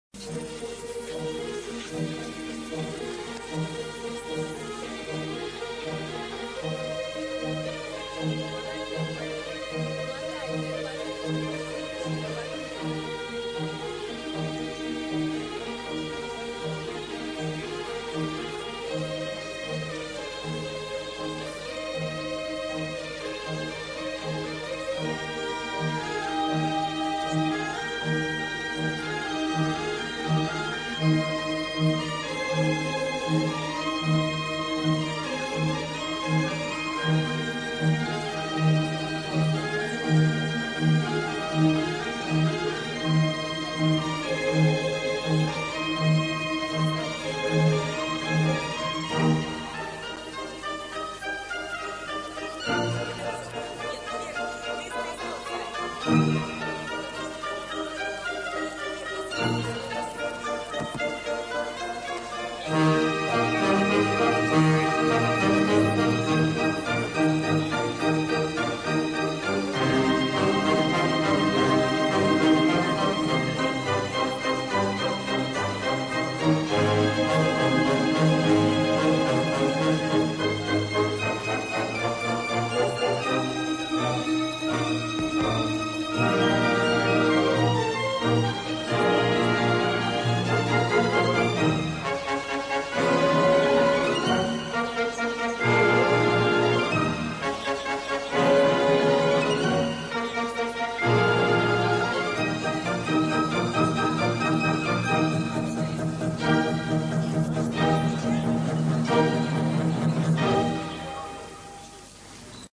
Помогите опознать запись кусочка балета... что это за балет, кто-нибудь знает?